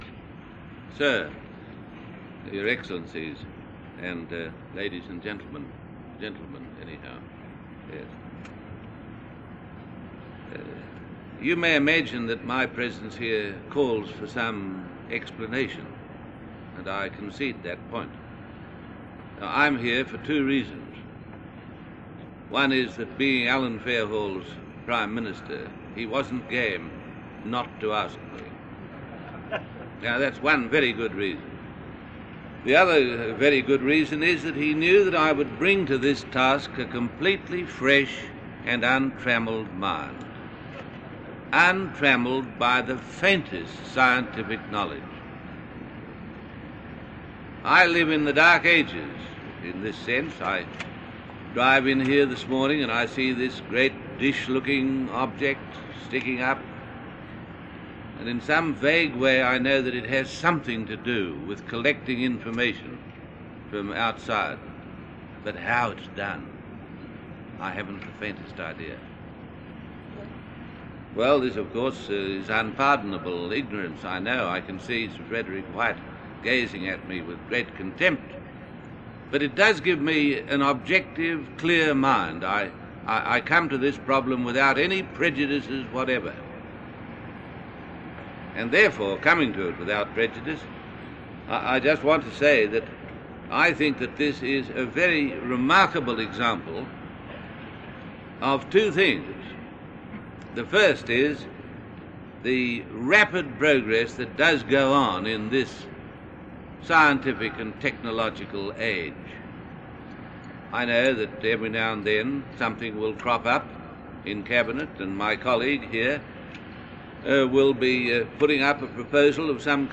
Australian Prime Minister Sir Robert Menzies.
Audio tape preserved in the National Archives of Australia.
05_Prime_Minister_Sir_Robert_Menzies.mp3